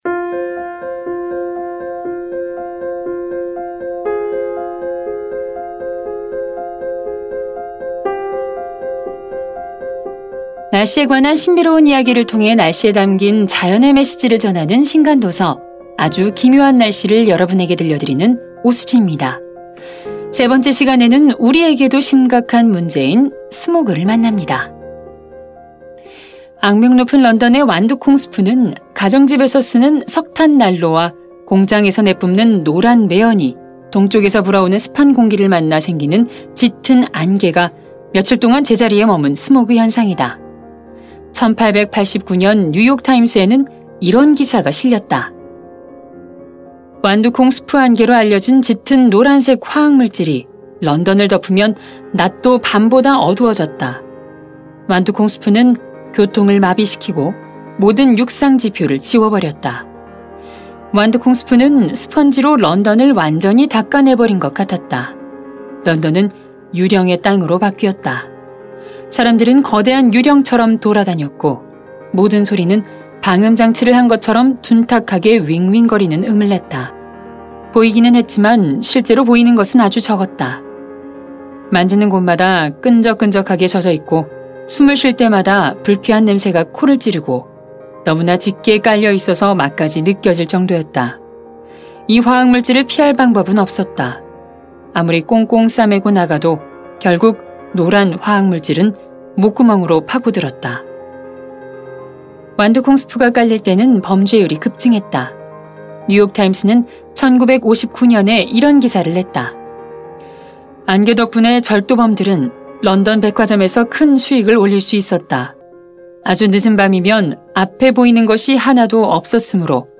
매거진 책 듣는 5분 ㅣ 오디오북